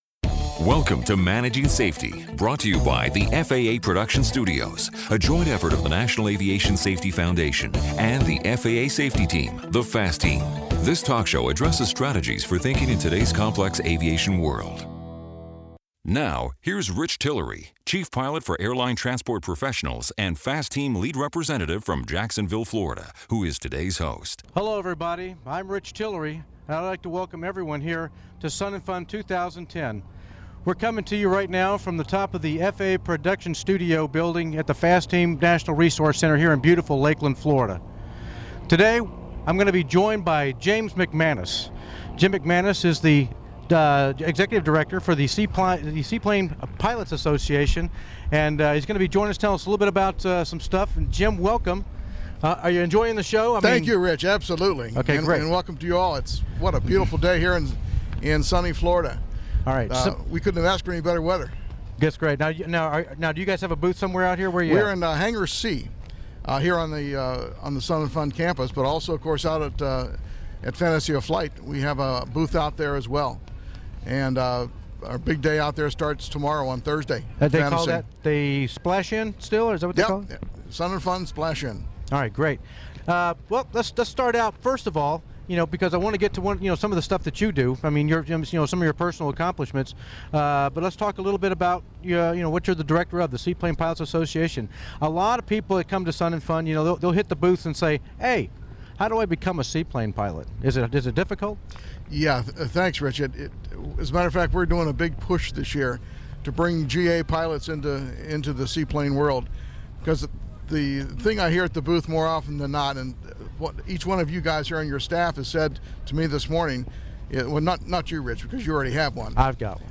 In this interview, they discuss soaring safely along with several interesting topics regarding unpowered flight. Recorded at the Soaring Society of America Safety Seminar.